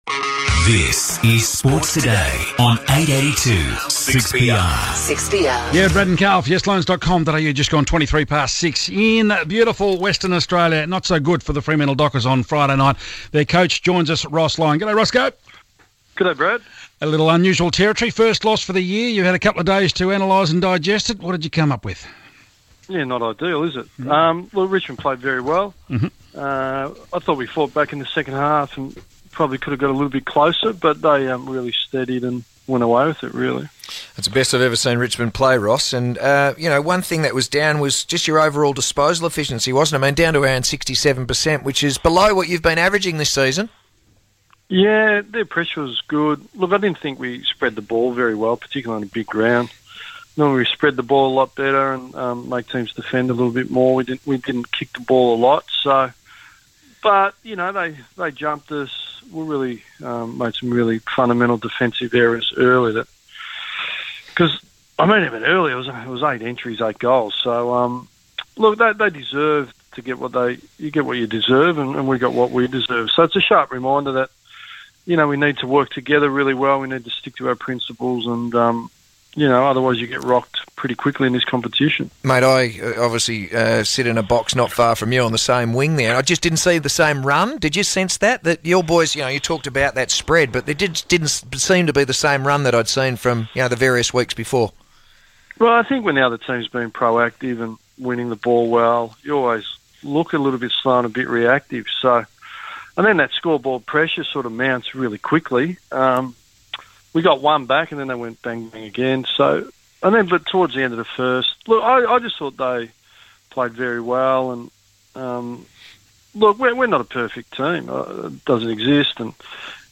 Ross Lyon joins 6PR Sports Today after Freo's first loss of the season.